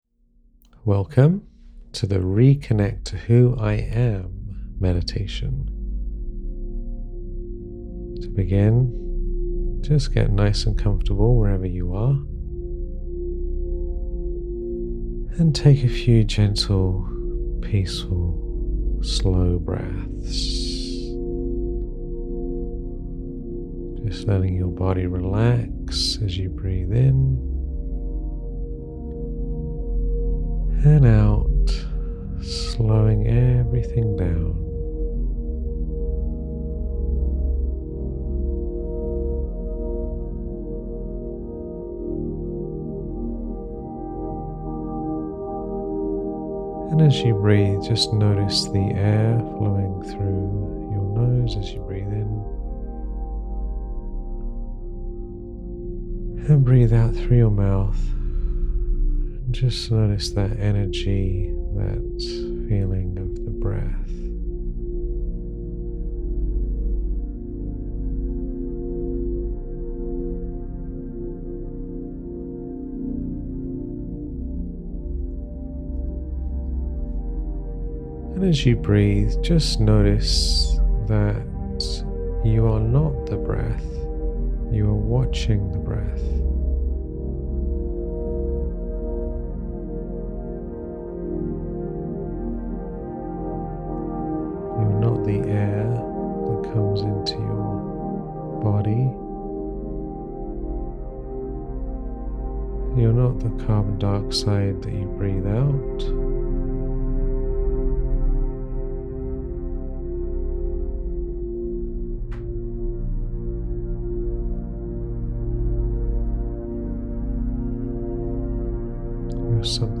[Meditation] Reconnect to who I really am